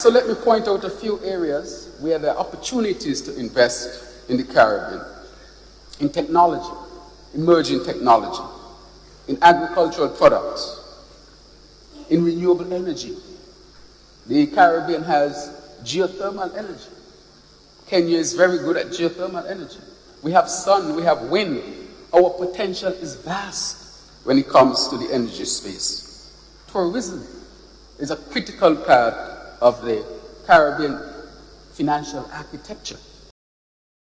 In an address at a high-level forum alongside African heads of state, the Prime Minister of Saint Kitts and Nevis, the Hon. Dr. Terrance Drew, made a call for deepened economic, cultural, and political ties between the African continent and its recognized sixth region of the Caribbean.